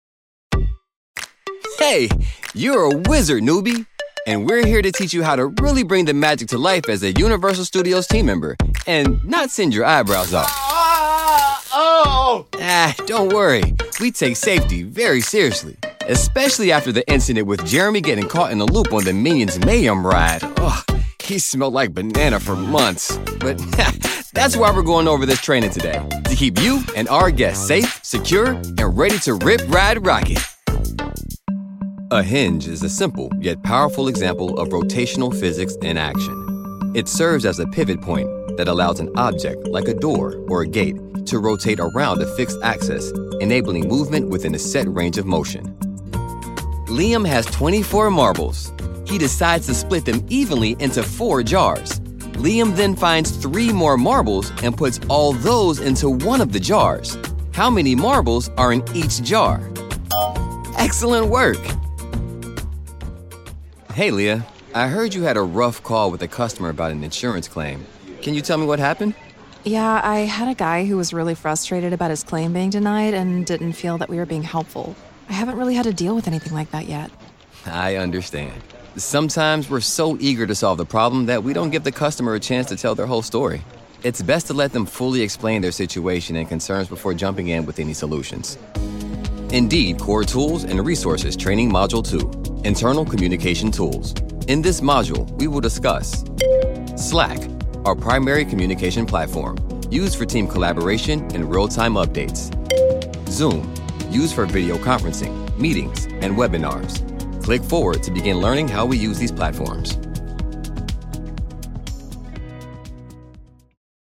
Now, Vibrant, straight talk.
eLearning